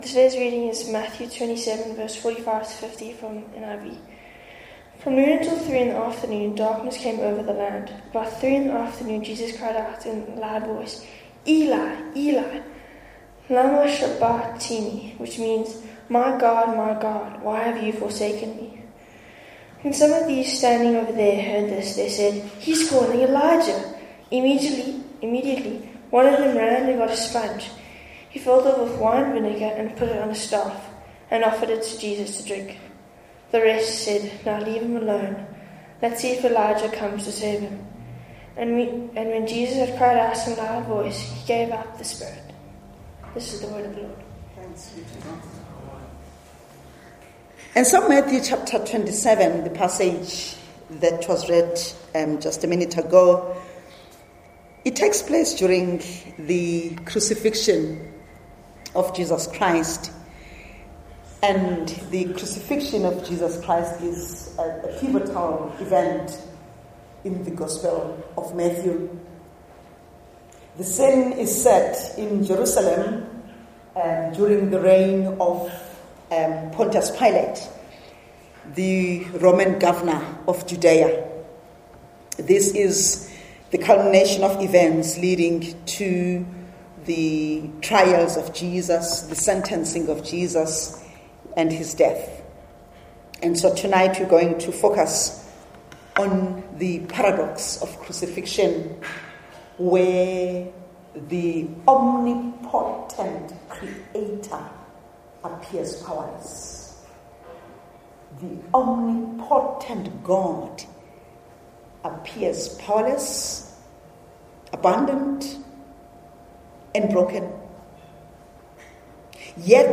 Trinity Methodist Church Sermons